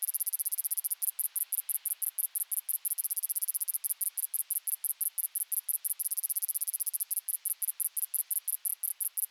grasshopper_loop.wav